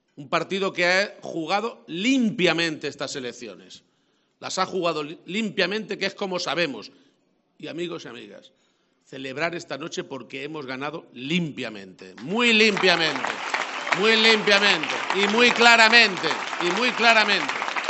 En el toledano Cigarral del Ángel Custodio, donde ha sido recibido al grito de “presidente”, Emiliano García-Page ha agradecido “de corazón” la contribución de “todos los que me habéis apoyado, me habéis ayudado y, por supuesto, a todos los que me habéis votado”, ha señalado.